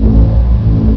game-source/ParoxysmII/sound/ambience/regen1.wav at b7dbb32b3ceaf482e88b4a2e51ba2e6ad36c8e16